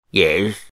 Вы найдете разные варианты: от радостного и энергичного до томного и шепотного «yes», записанные мужскими, женскими и детскими голосами.
Yes стариковский